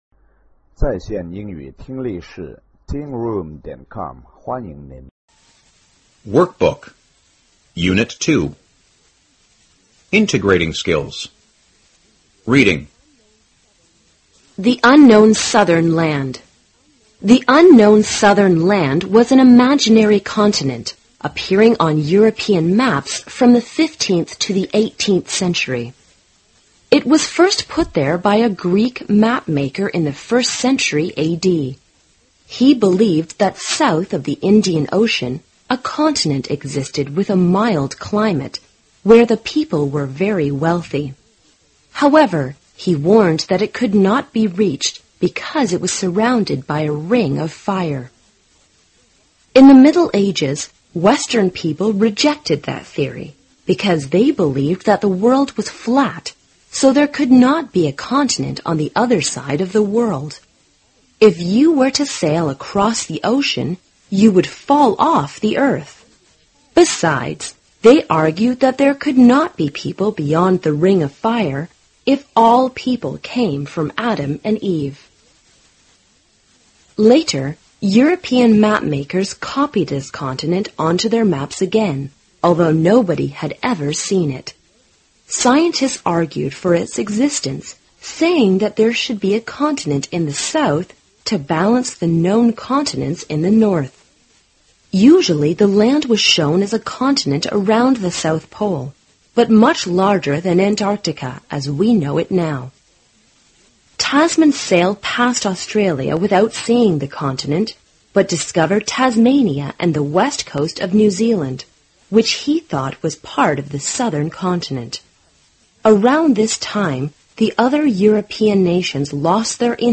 高中英语第三册课本朗读workbook-unit2 听力文件下载—在线英语听力室